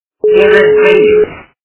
» Звуки » Люди фразы » Фраза и фильма - Я вас боюсь
При прослушивании Фраза и фильма - Я вас боюсь качество понижено и присутствуют гудки.